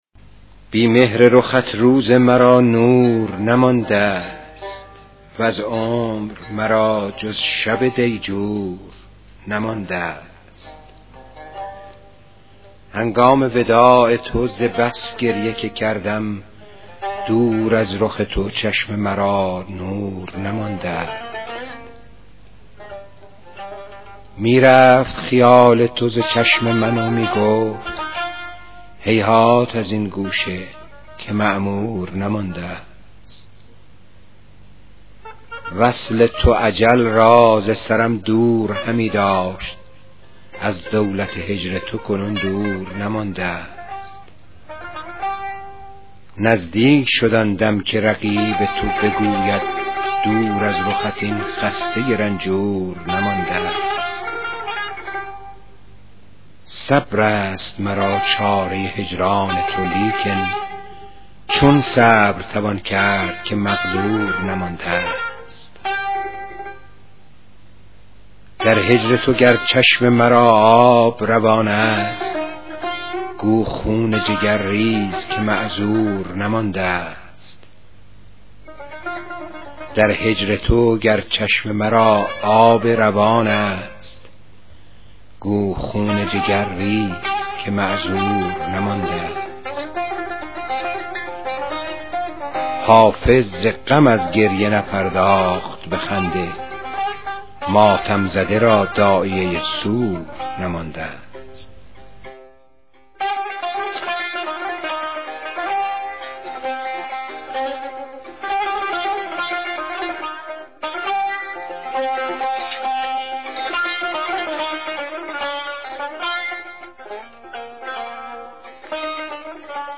دکلمه غزل شماره 38 دیوان حافظ شیرازی